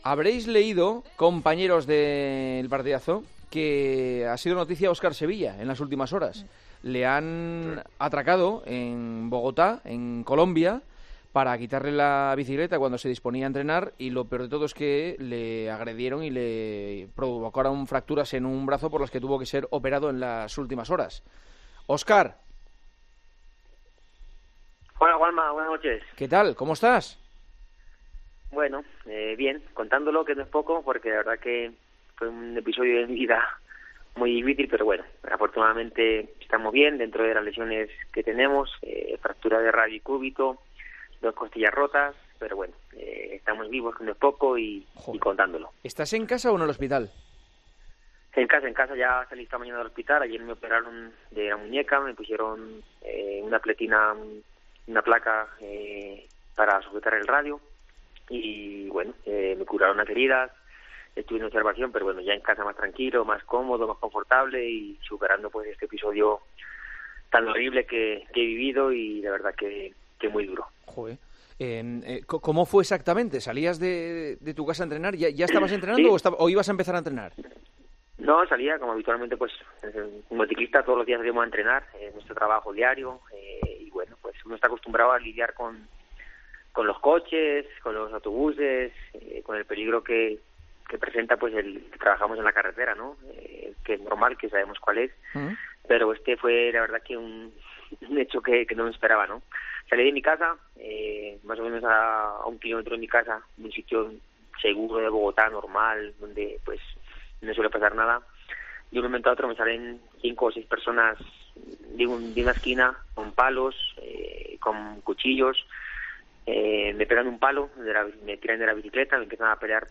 El ciclista español Óscar Sevilla fue noticia el pasado fin de semana por la paliza que recibió de un grupo de delincuentes que le asaltó, le agredió salvajemente y le robó varias pertenencias. " Estoy contándolo, que no es poco. Ha sido un capítulo de mi vida difícil ", dijo este lunes en El Partidazo de COPE , ya desde su casa en Colombia.